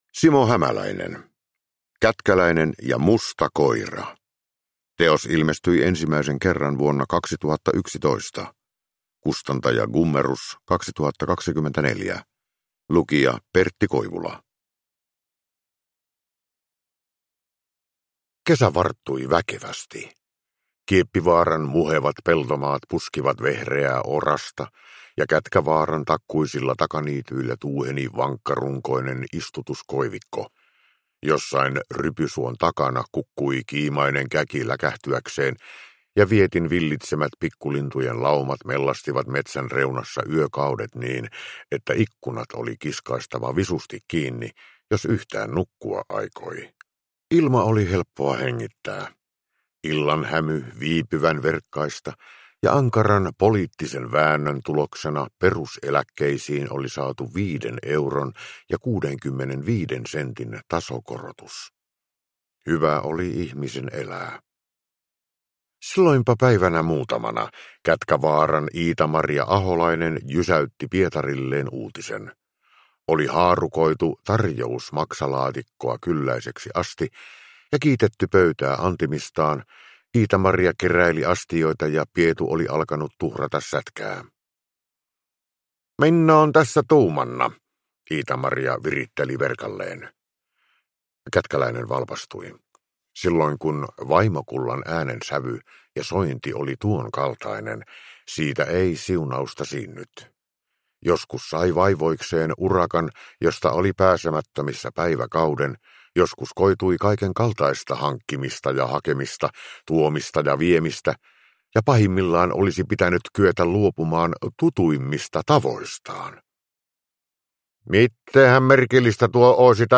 Kätkäläinen ja musta koira – Ljudbok
Uppläsare: Pertti Koivula